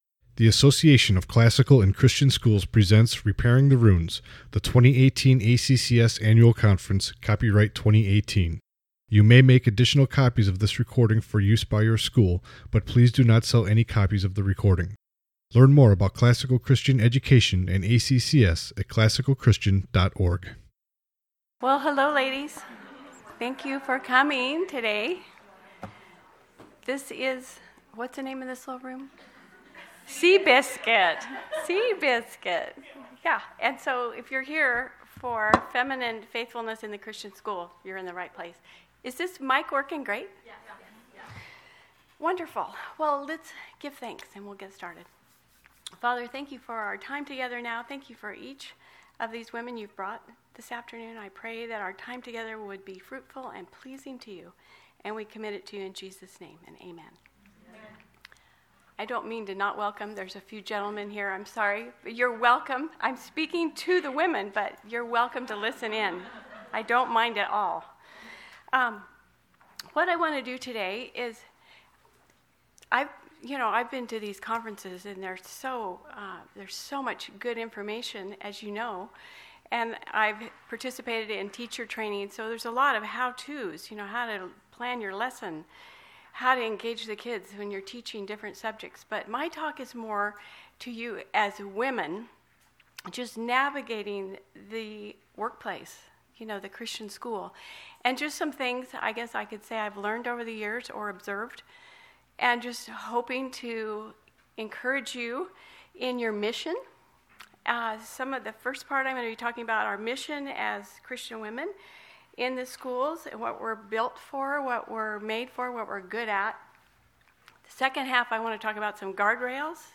2018 Workshop Talk | 59:27 | All Grade Levels, General Classroom